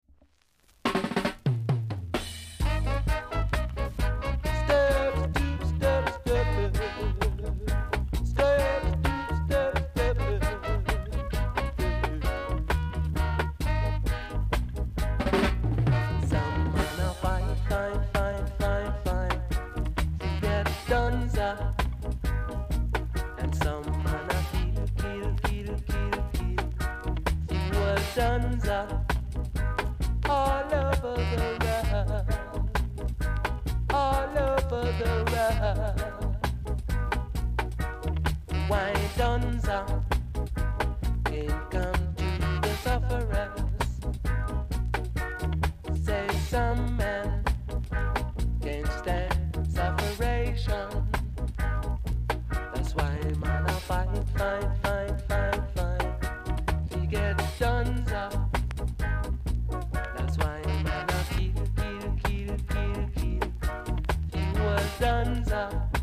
ROOTS CLASSIC!!